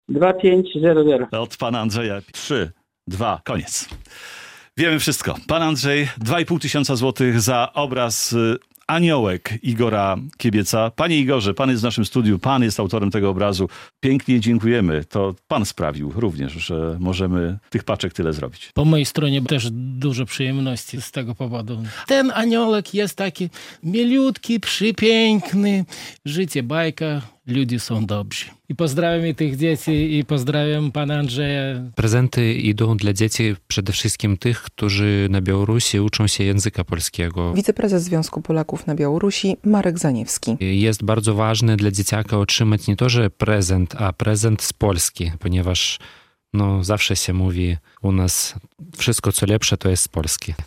4 tys. zł udało się zebrać podczas pierwszego dnia licytacji obrazów polskich artystów z Białorusi - relacja